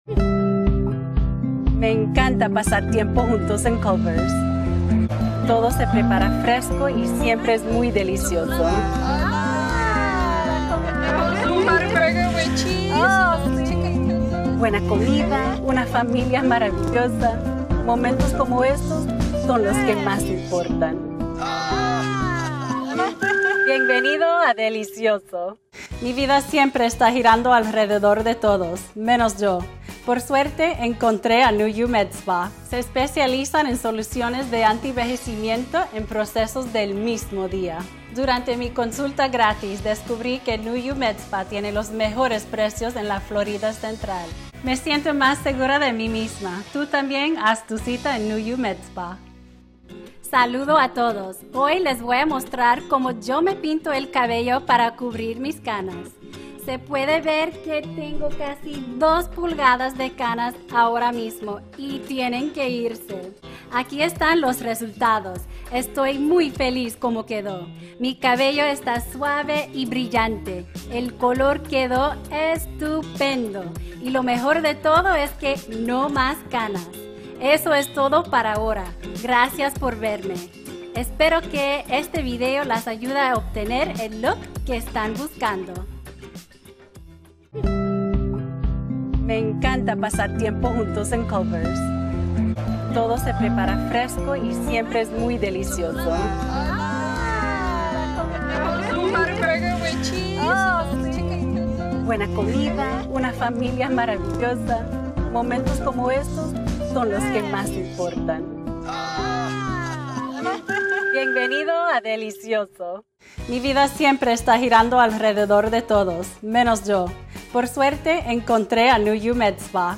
Female Voice Over, Dan Wachs Talent Agency.
Bilingual Voice Actor, Neutral Spanish.
Commercial - Spanish